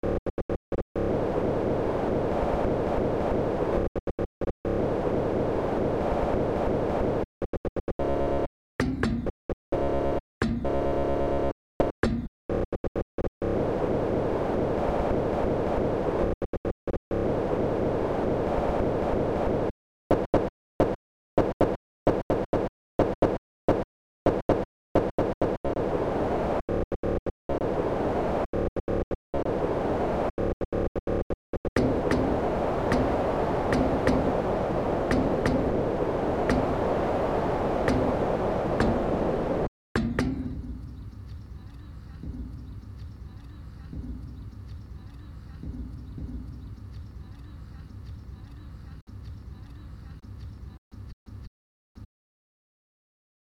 weird, experimental, glitch,